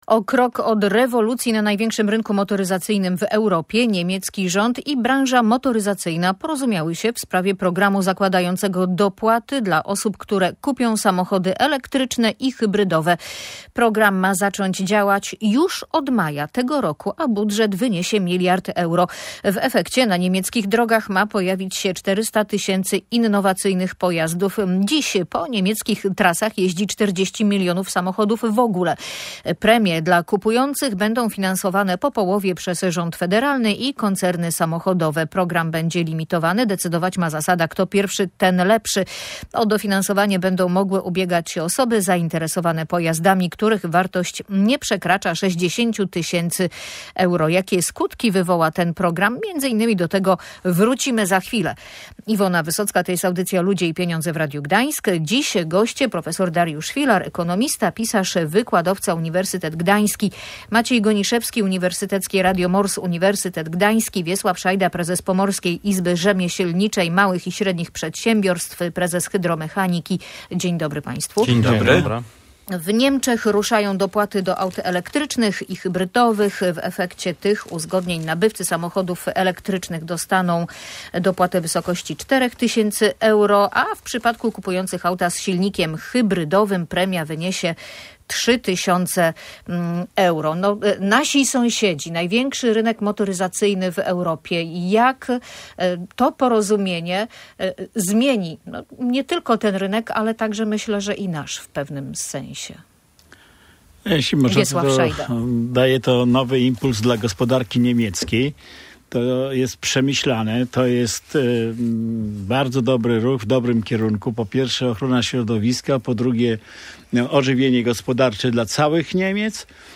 Czy na targach pracy można znaleźć dobrego pracownika lub pracę? Rozmawiali o tym goście audycji Ludzie i Pieniądze.